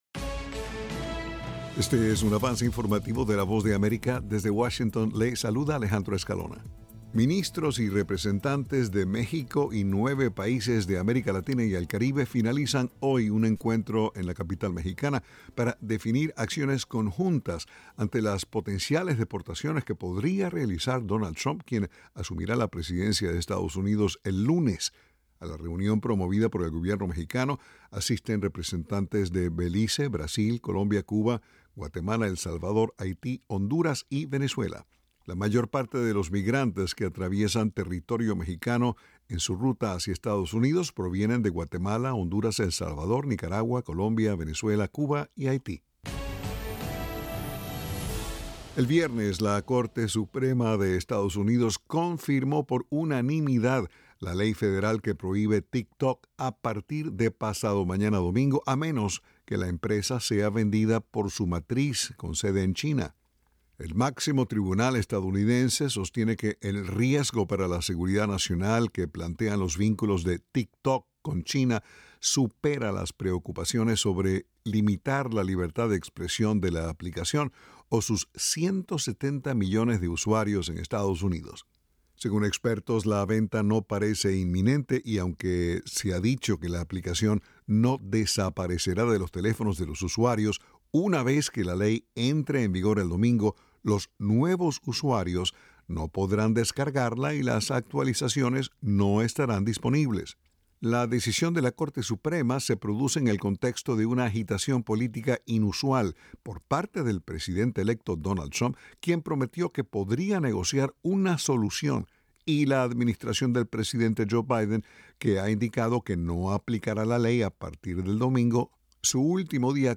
Avance Informativo
El siguiente es un avance informativo de la Voz de América.